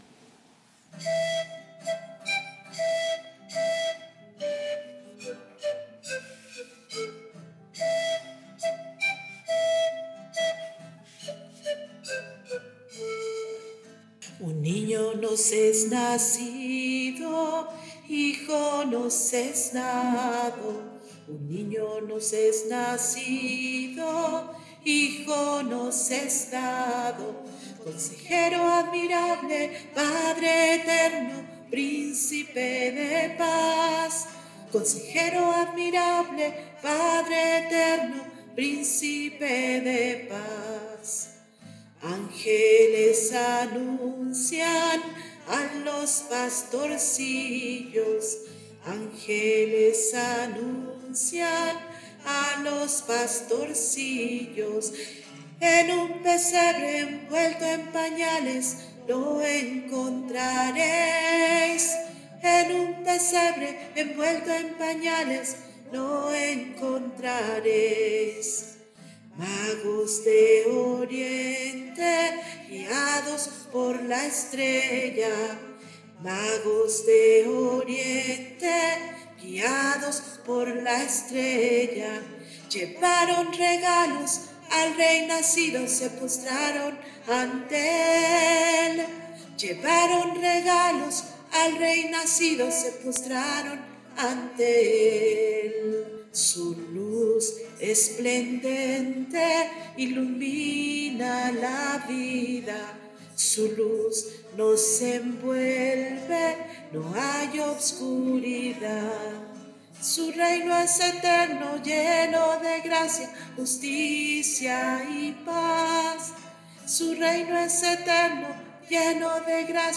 Creado para la liturgia «Miren la estrella, hay esperanza», Red Crearte México, 2024
Audio voz: